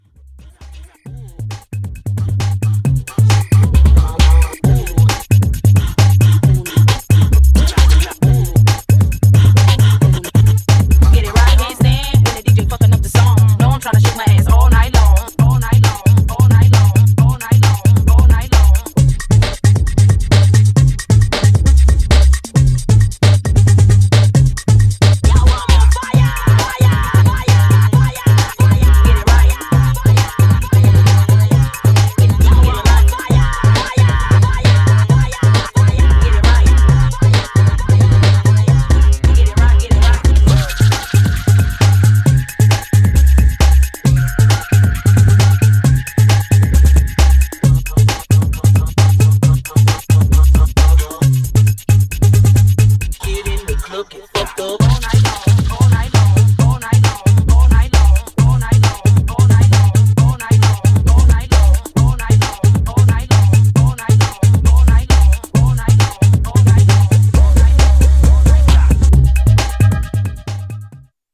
Styl: Hip Hop, House